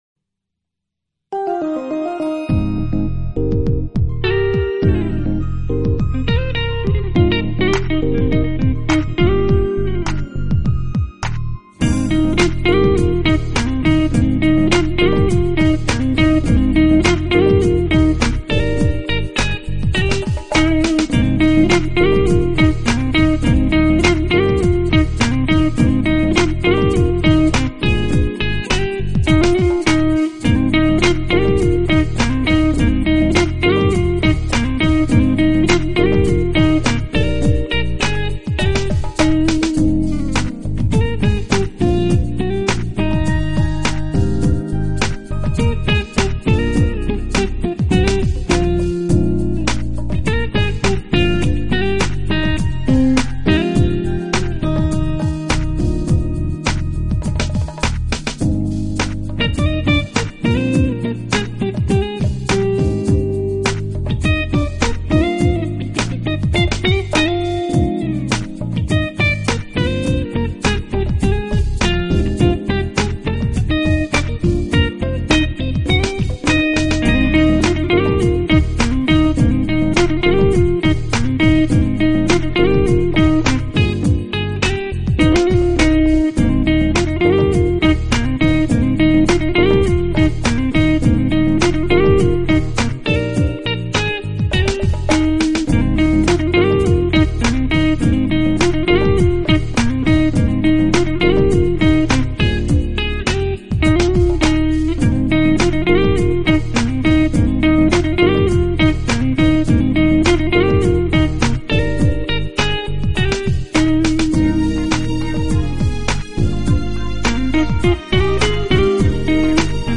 Genre: Jazz Style: Smooth Jazz